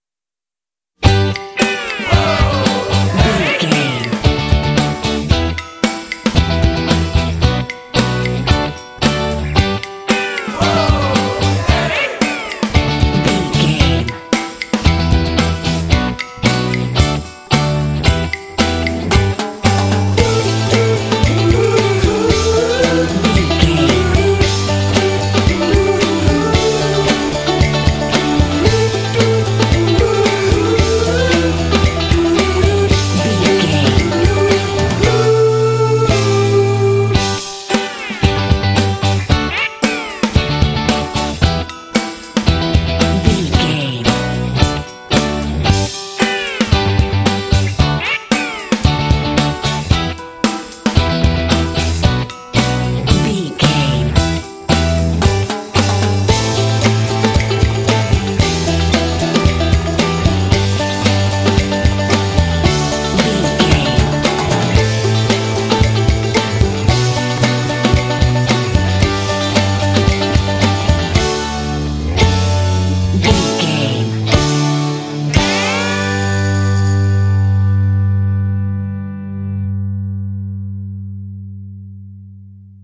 Uplifting
Ionian/Major
driving
bouncy
groovy
electric guitar
bass guitar
drums
vocals
electric organ
alternative rock
indie